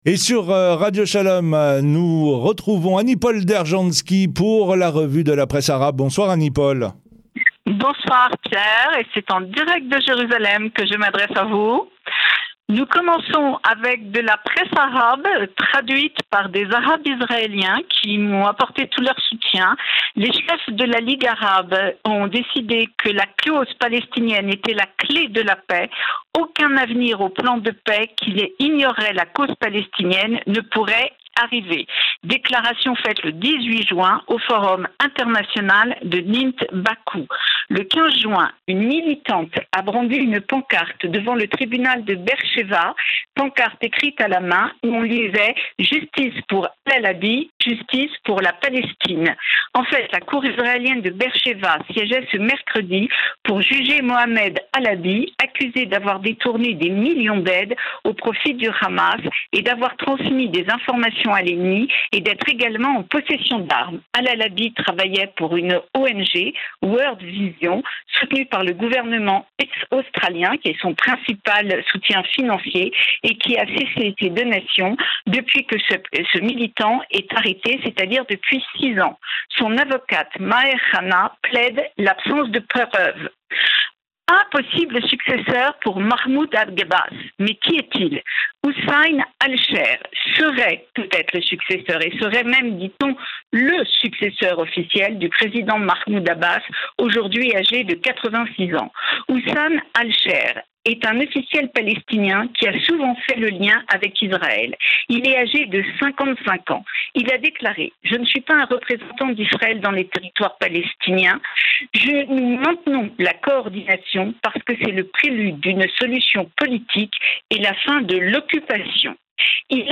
RADIO SHALOM EN DIRECT